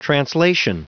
Prononciation du mot translation en anglais (fichier audio)
Prononciation du mot : translation